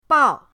bao4.mp3